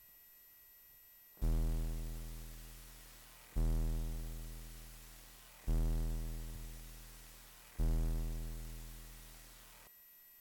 The combination of low frequency and the triangle wave makes very evident the presence of aliasing in some of them.
test-fpgasid-8580-dac-linear.mp3